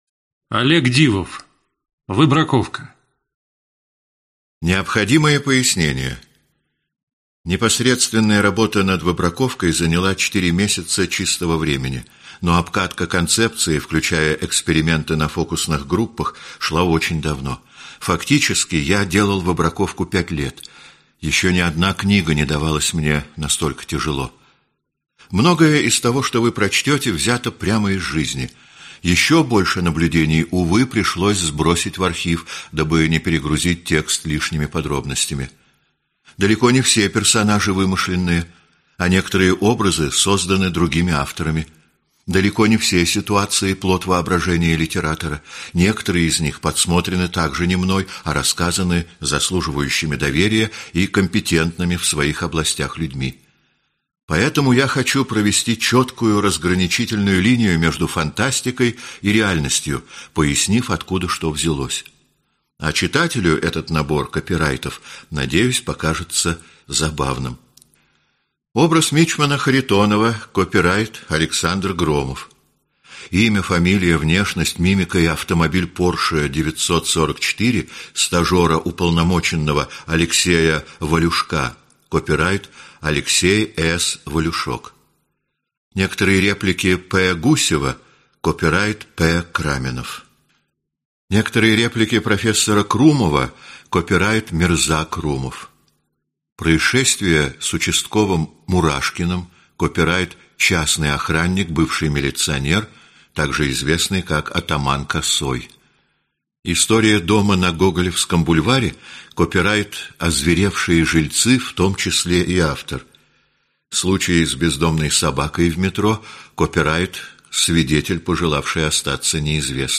Аудиокнига Выбраковка | Библиотека аудиокниг